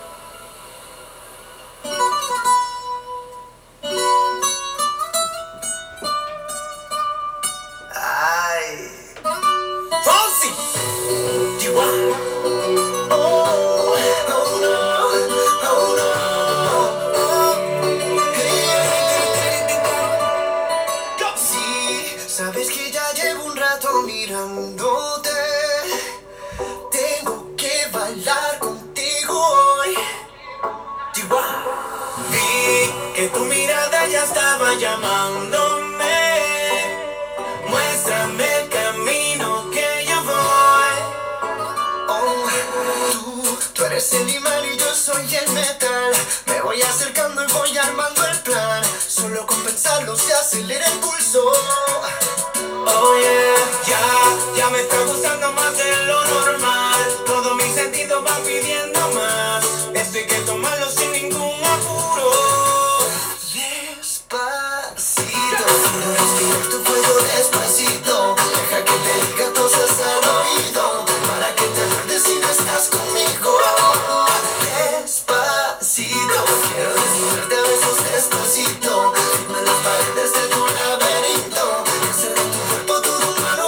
Piezo-speaker-Composite-panel-F.mp3